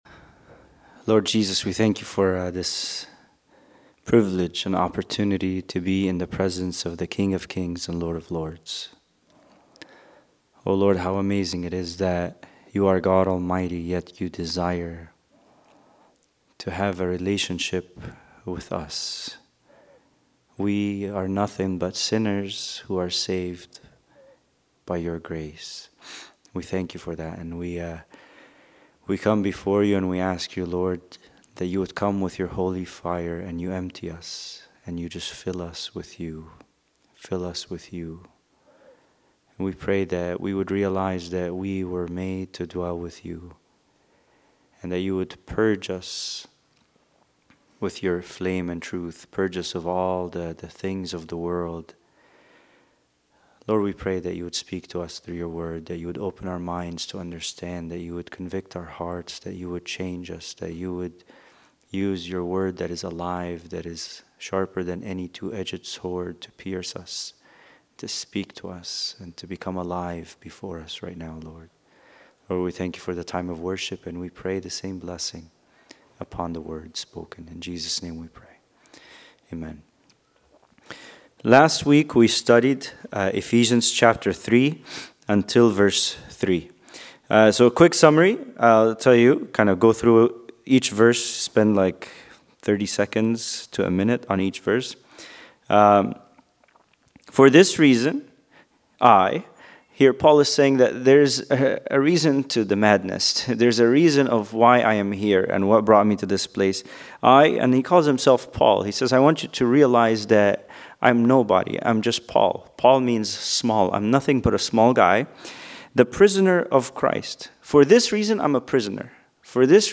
Bible Study: Ephesians 3:4-6